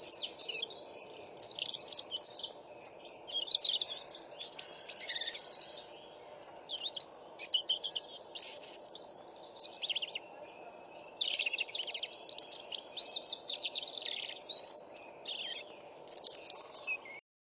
知更鸟叫声 欧亚鸲鸣叫